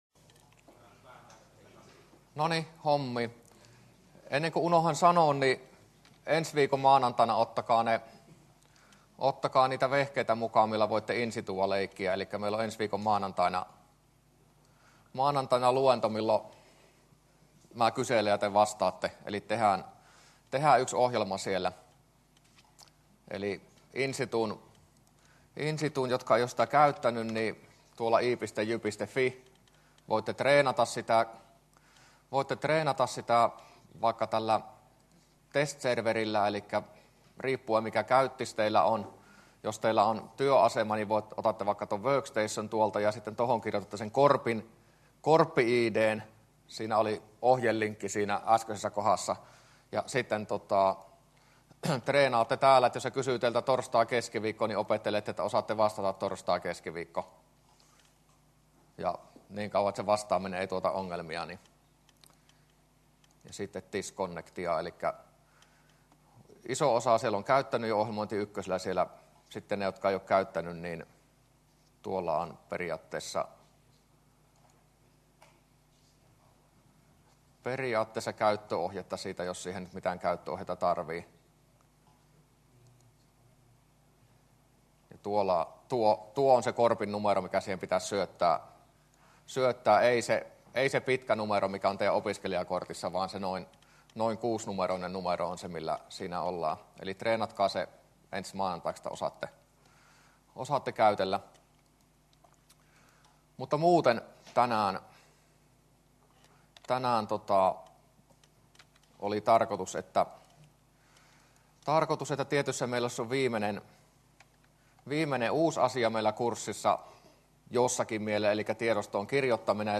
luento18a